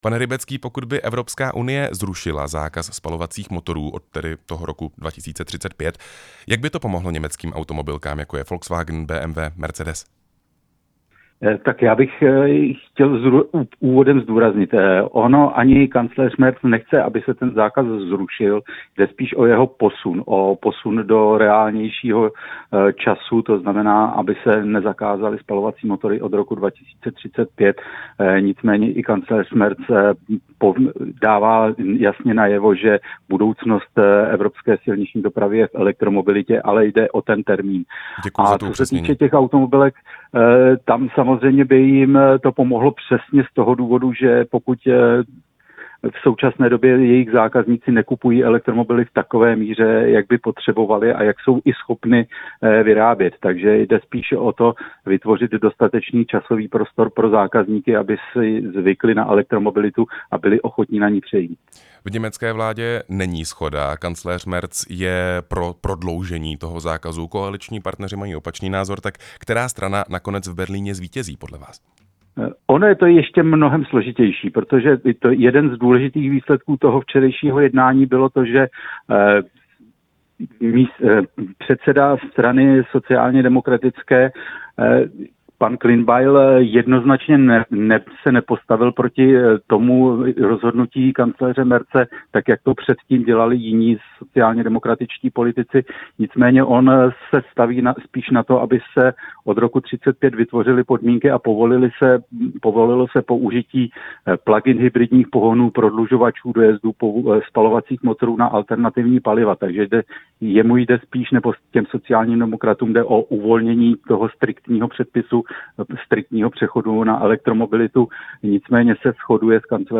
Rozhovor s motoristickým novinářem